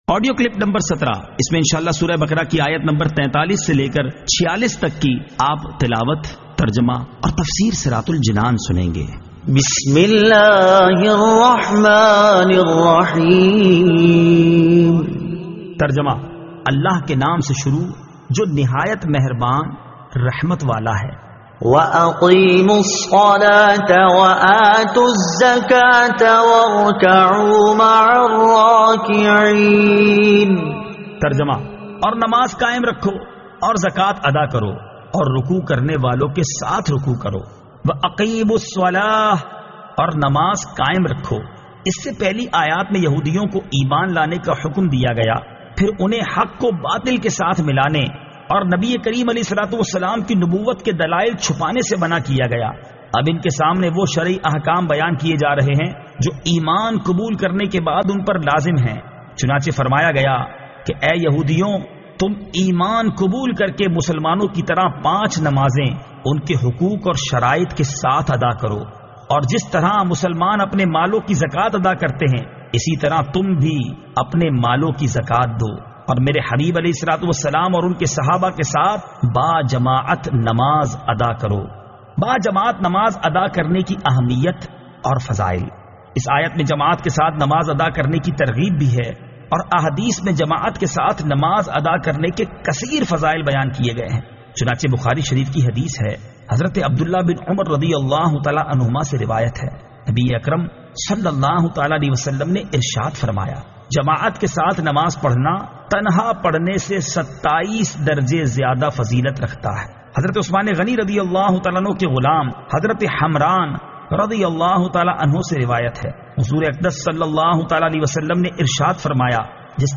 Surah Al-Baqara Ayat 43 To 46 Tilawat , Tarjuma , Tafseer